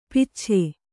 ♪ picche